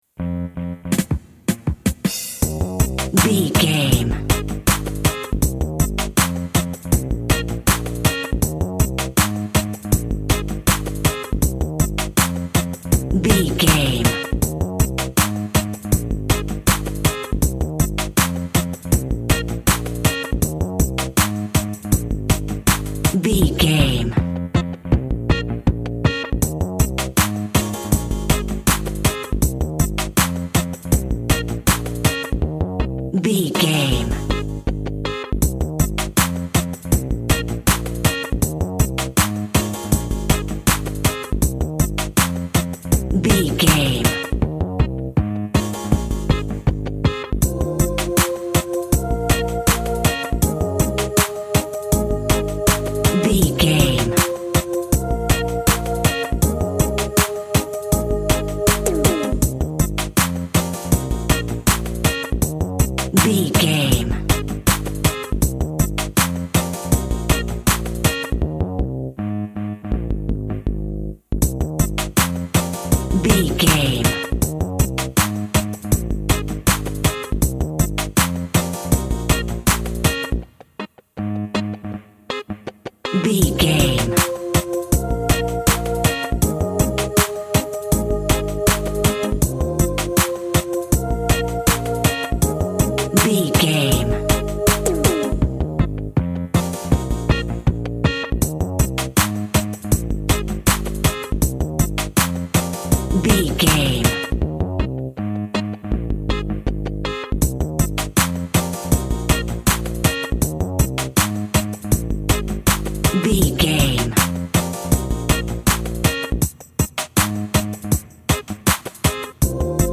Also with small elements of Dub and Rasta music.
Ionian/Major
A♭
tropical
reggae
bass
guitar
piano
brass
pan pipes
steel drum